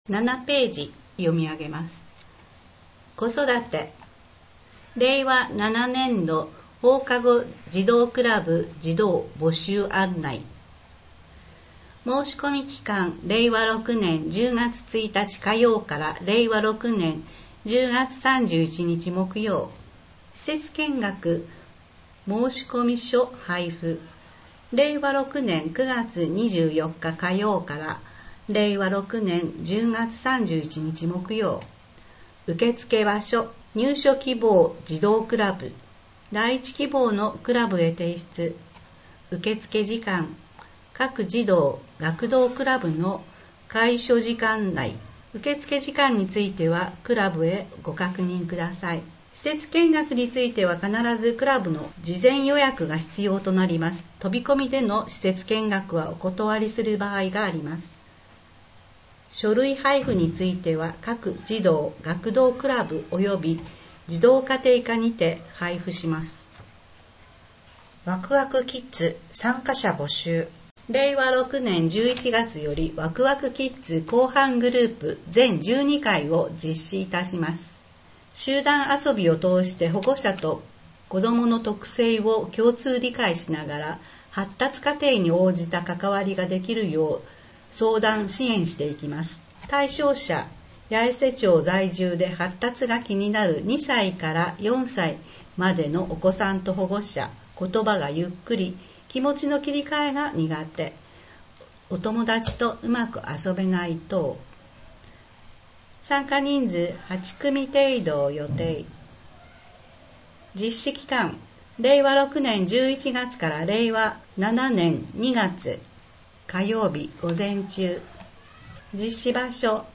声の「広報やえせ」　令和6年10月号226号